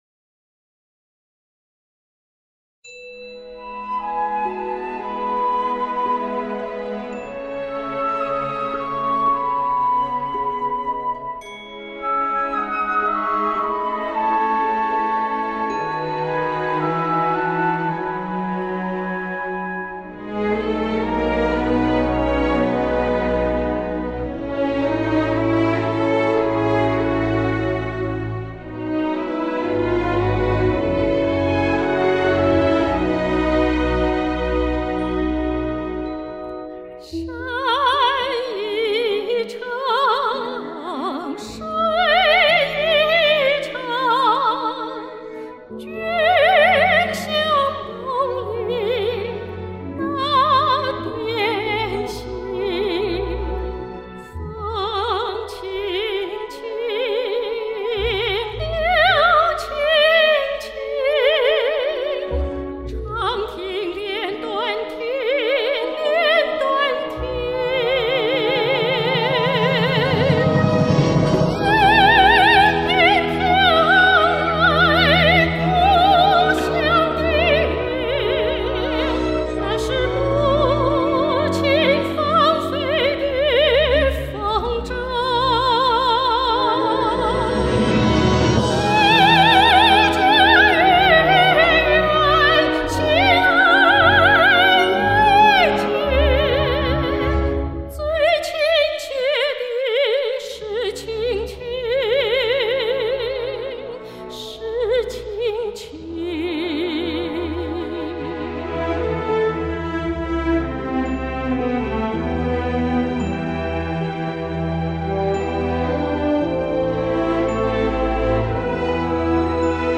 珍珠般的空灵，绝美歌声
哇，细腻而优美动听的演唱令人喜爱！
优美优雅的美声，沁人心脾！！！